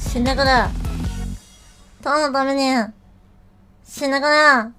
Worms speechbanks
Fatality.wav